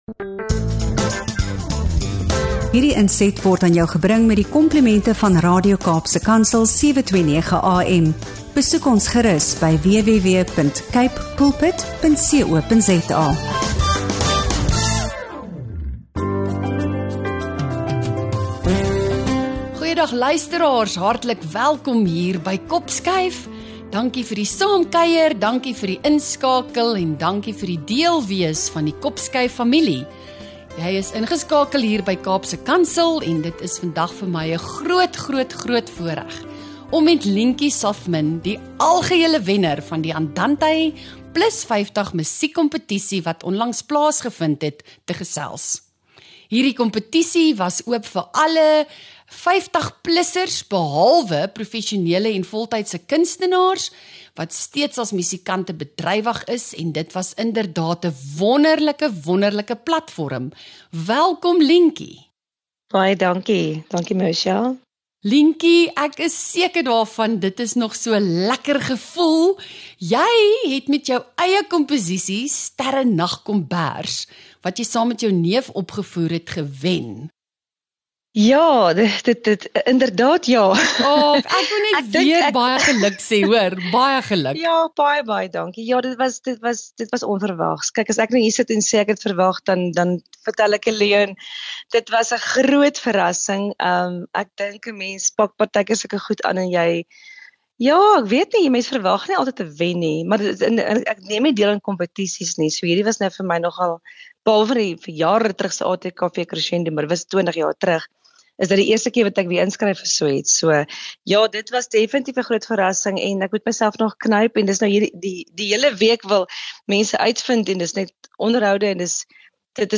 ’n Innemende gesprek oor kreatiwiteit, Afrikaanse musiek en die krag van oorspronklike komposisies.